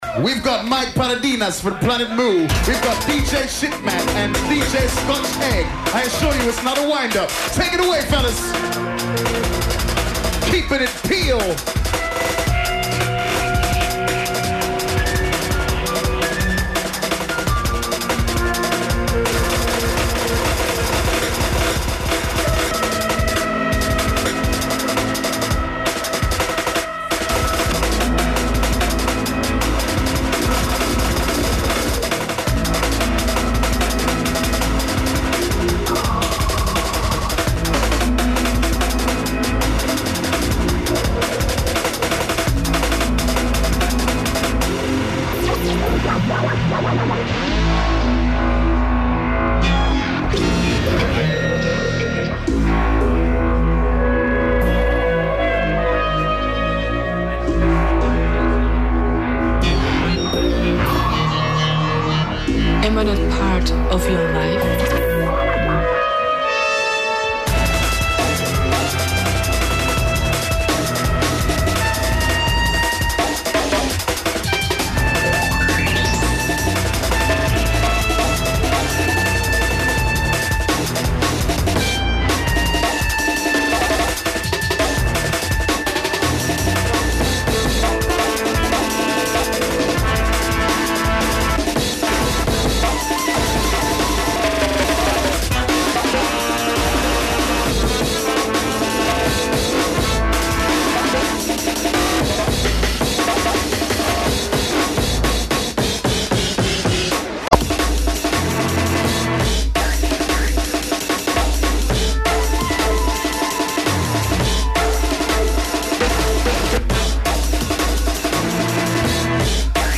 venue Maida Vale